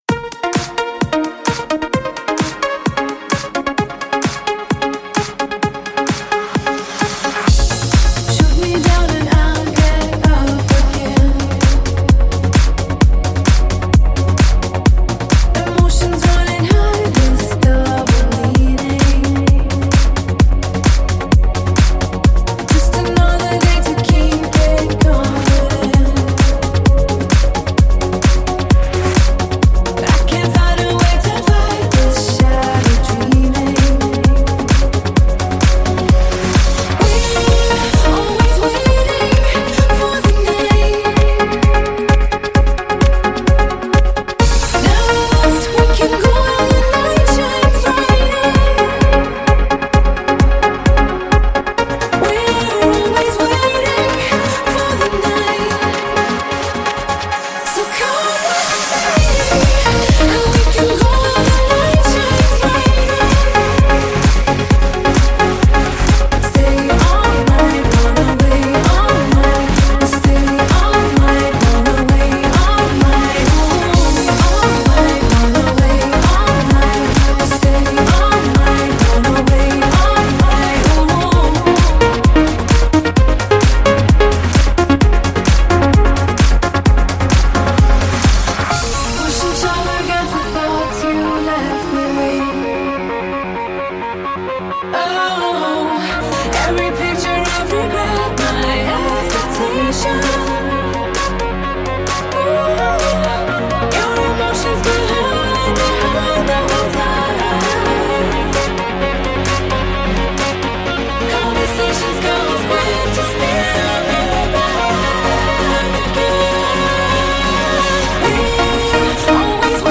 Клубная музыка
вокал транс музыка